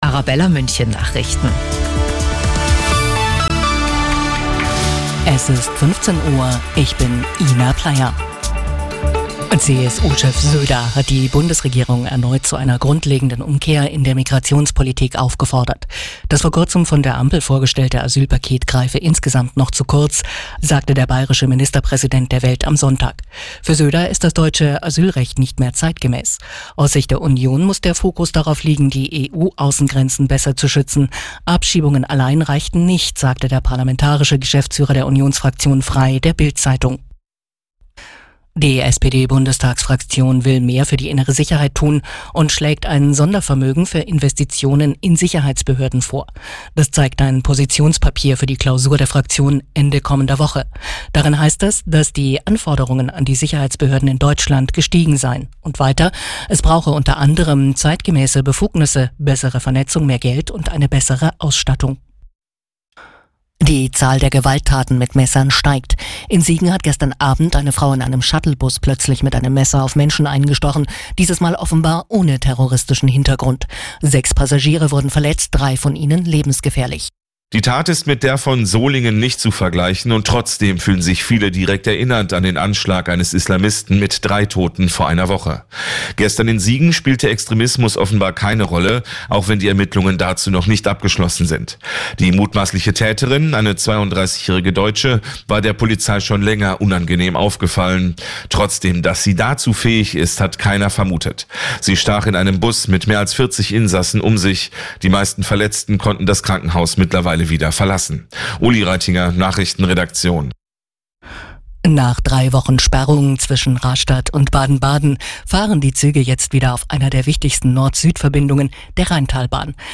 Die Arabella Nachrichten vom Samstag, 31.08.2024 um 15:59 Uhr - 31.08.2024